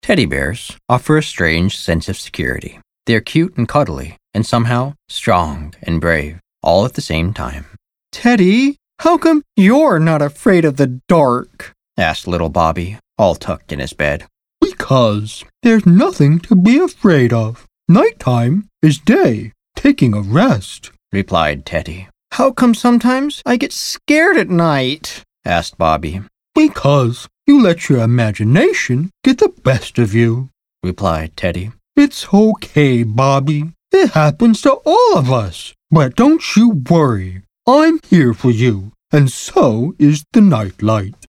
Samples that reveal my full range: intimate storytelling, rich narration, and bold character voices.
Children's Reading Demo